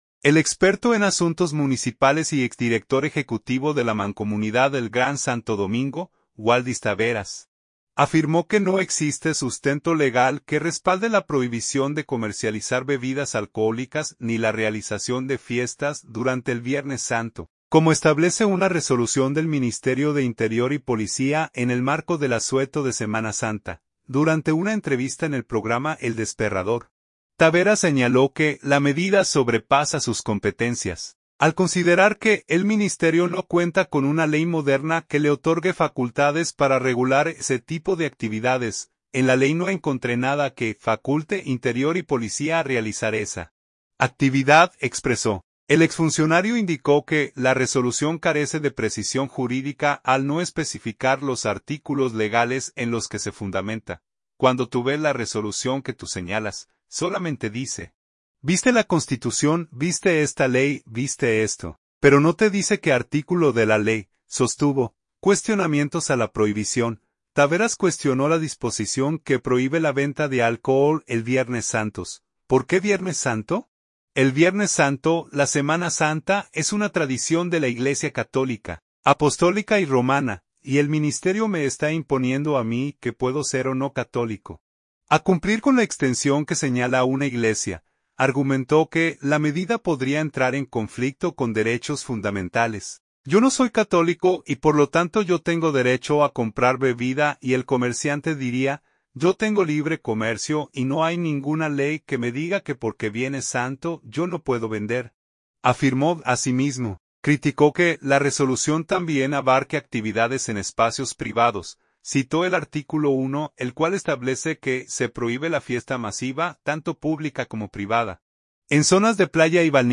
Durante una entrevista en el programa El Desperrador